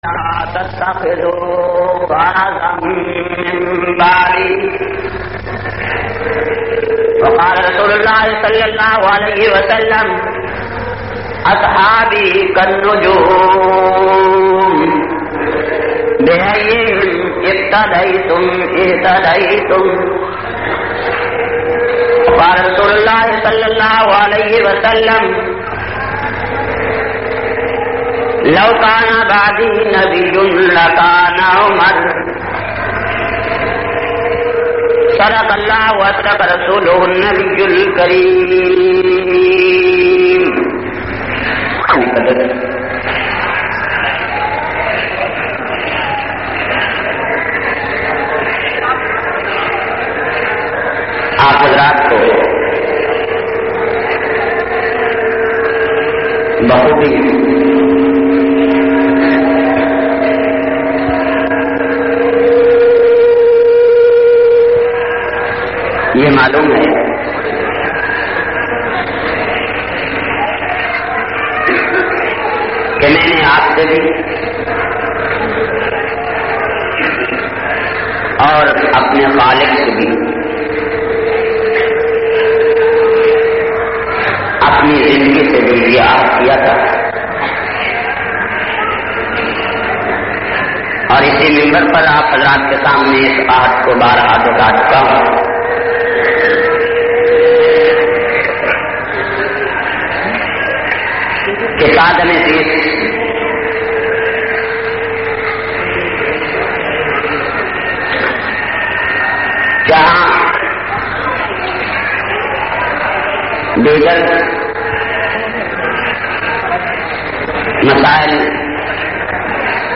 264- Zalim kon Mazloom kon Khutba Jumma Jhang.mp3